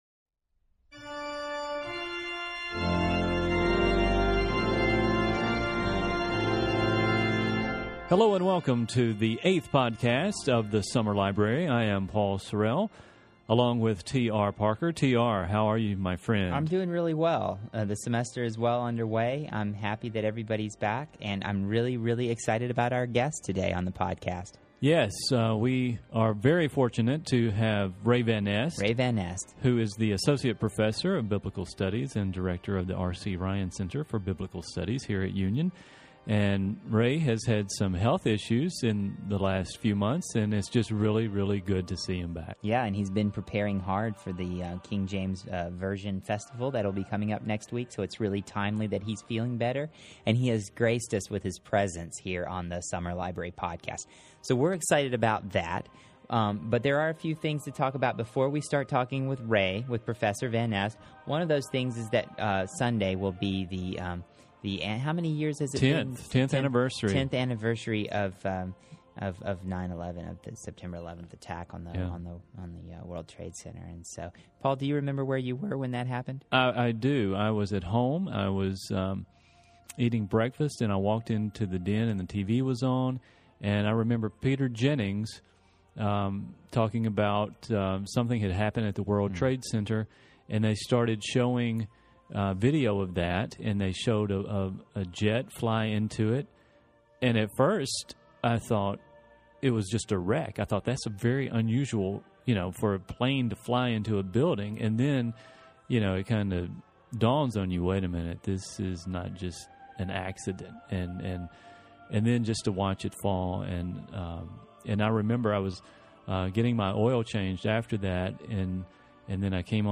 The music used in this episode is "I was Glad" composed by Orlando Gibbons, who was born in Oxford in 1583 and attended King's College, Cambridge to study music.